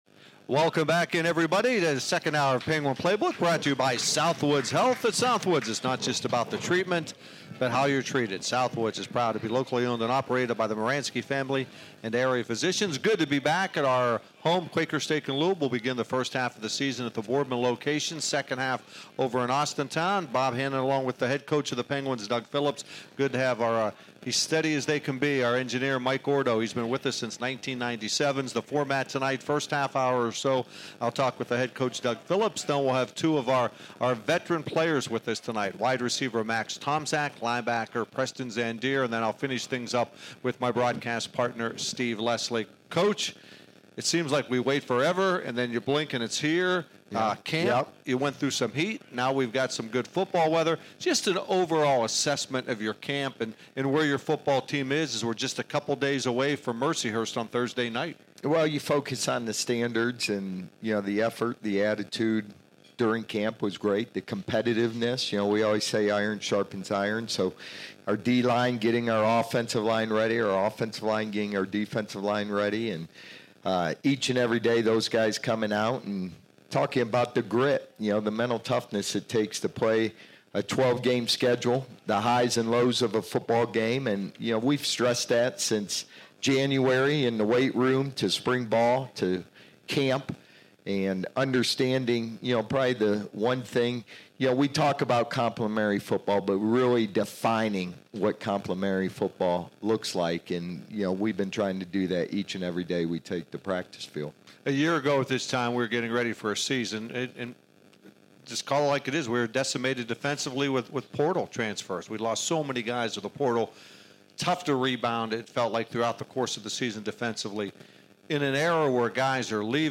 Penguin Playbook Postgame Interview